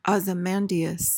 PRONUNCIATION:
(oz-uh-MAN-dee-uhs)